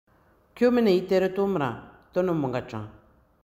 Lecture et prononciation